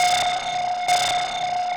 K-5 Noisy Noise.wav